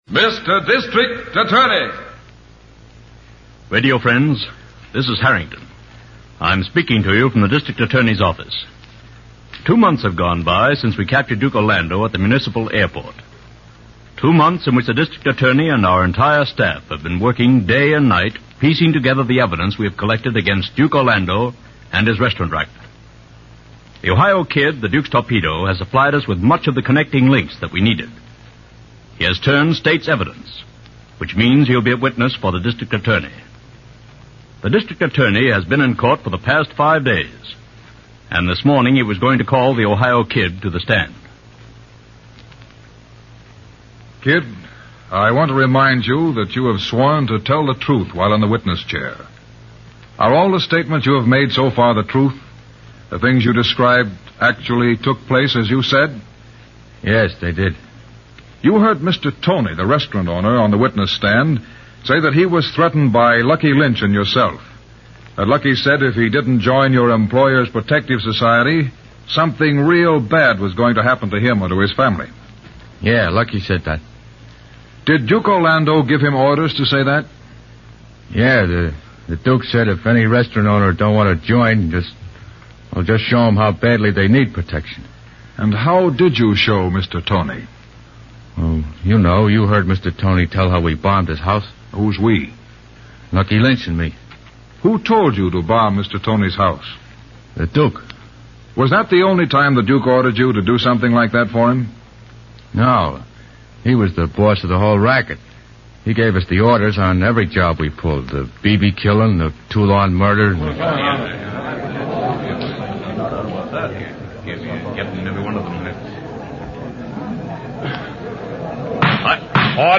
District Attorney is a radio crime drama, produced by Samuel Bischoff, which aired on NBC and ABC from April 3, 1939, to June 13, 1952 (and in transcribed syndication through 1953).